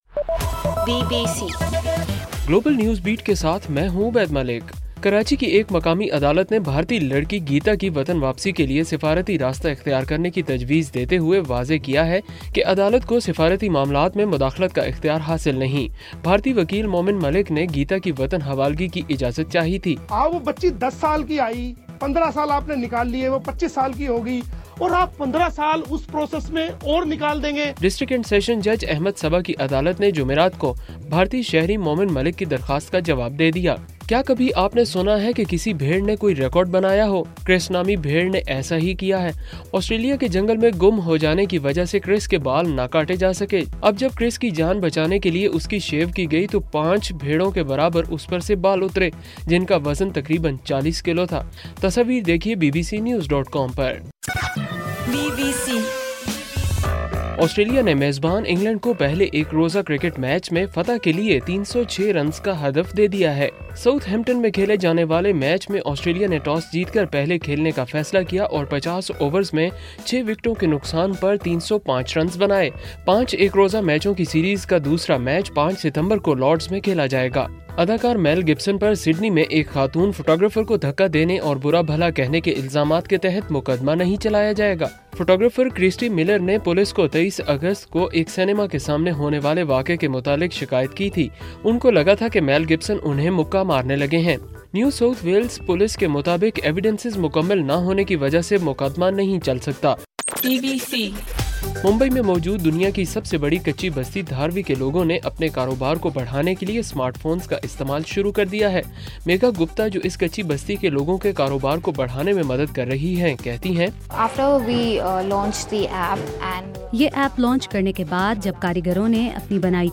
ستمبر 4: صبح 1 بجے کا گلوبل نیوز بیٹ بُلیٹن